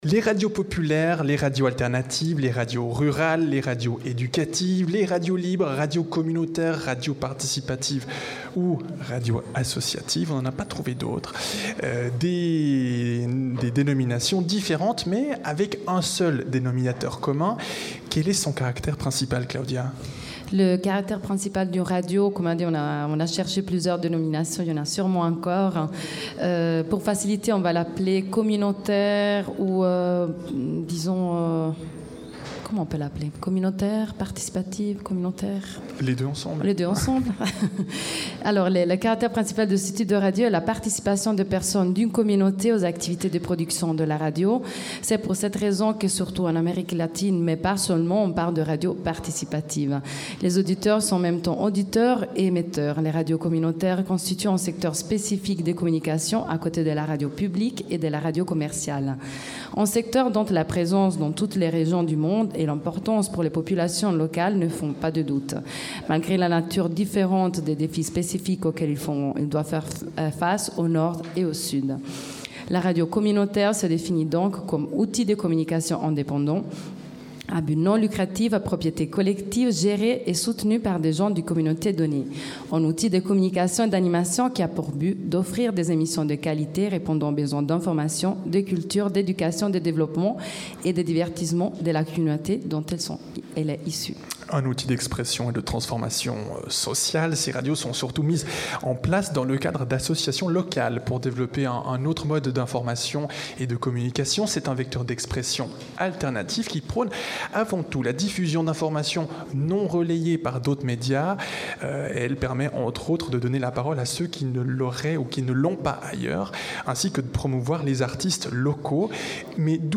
A cette occasion, Radio Django vous invite à prendre part à cette émission spéciale le mardi 29 octobre 2019 de 18h à 19h30 en direct de nos studios à Pôle Sud, avec un menu Django’sien.
200ème-Débat-Radios-communautaires-et-de-proximités.mp3